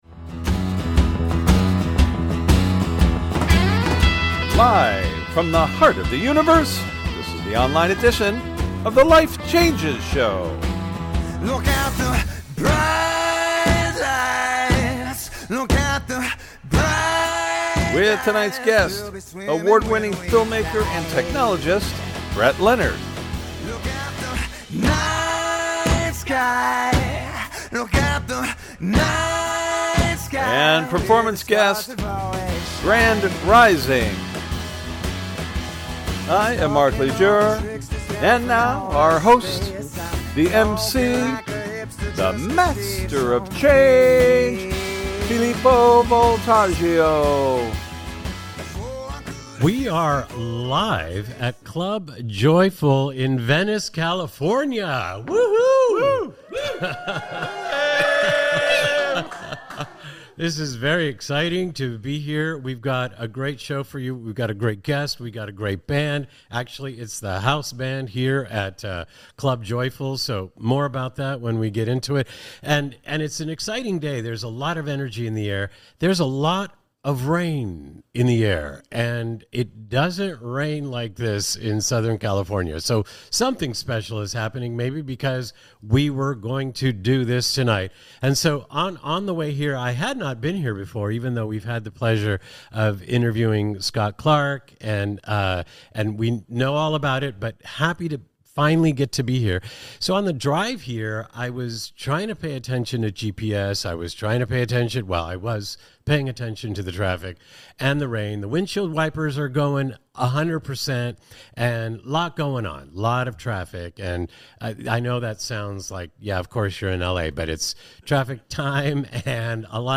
Featuring Interview Guest, Visionary Director / Producer / Futurist, Award-Winning Film Maker and Technologist, Thought-Leader in Virtual Reality and A.I., Brett Leonard; and Performance Guests, LA Based Band and Platform Committed to Inspiring and Encouraging Humanity to Live in a State of Love, Light, and Peace, Grand Rising, on The Life Changes Show, Episode 868